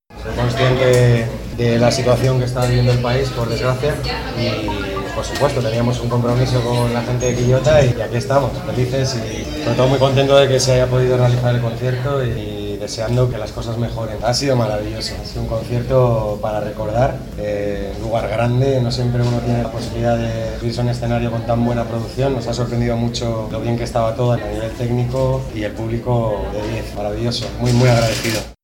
Finalizado el concierto, el español fue consultado sobre la inmediata confirmación de su participación en la Expo, luego que esta fuera suspendida hasta esta semana, por el estallido social de octubre.